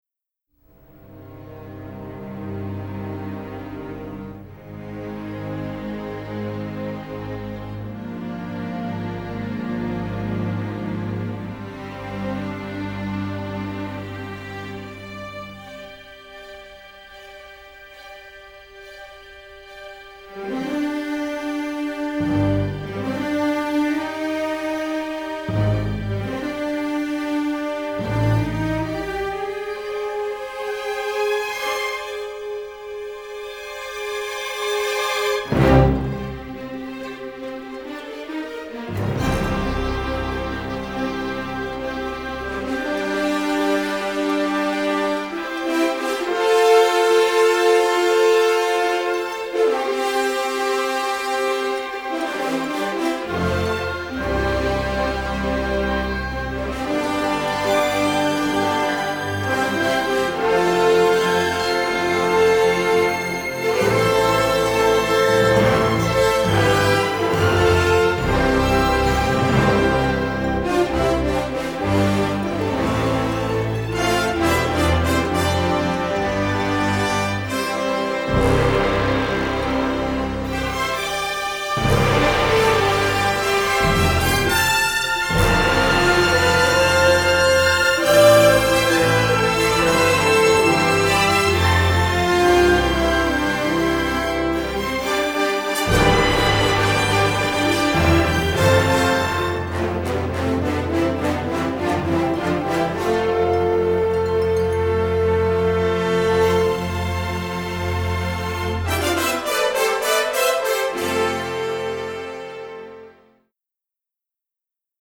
Everything has been remastered from superior master elements